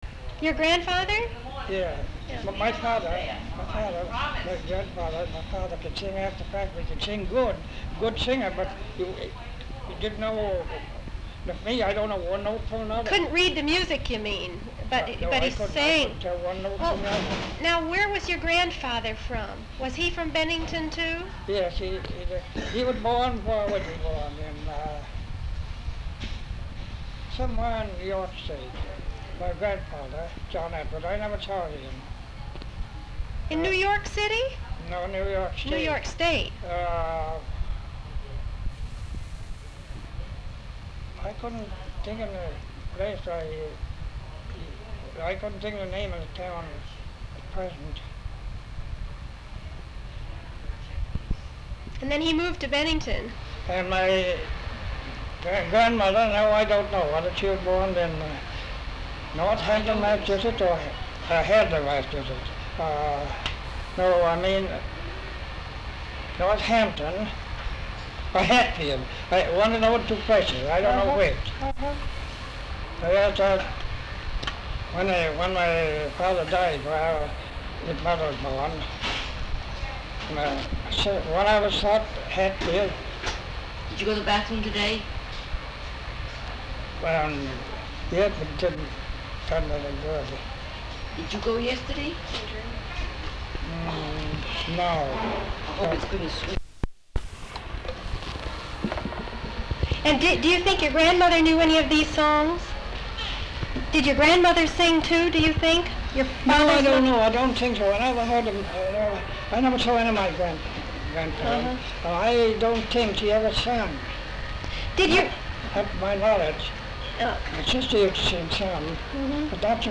sound tape reel (analog)
Windham, Connecticut